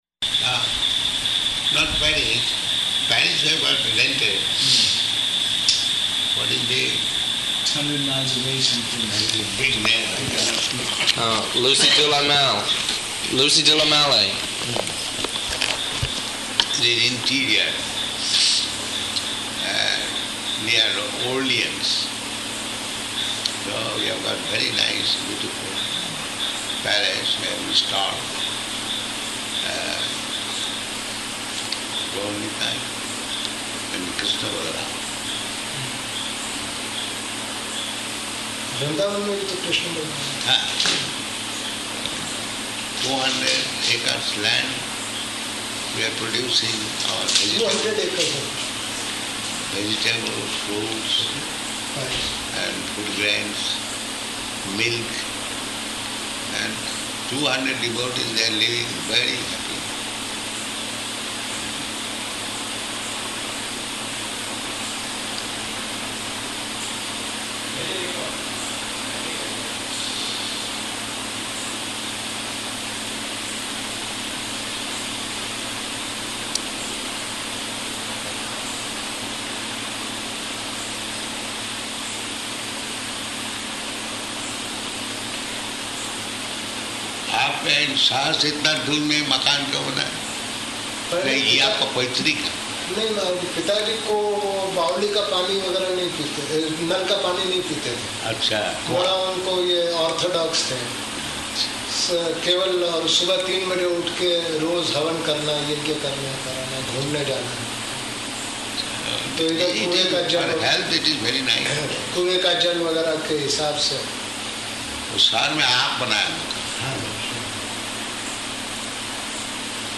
Evening Darśana on night of arrival --:-- --:-- Type: Lectures and Addresses Dated: August 16th 1976 Location: Hyderabad Audio file: 760816AR.HYD.mp3 Prabhupāda: ...not Paris.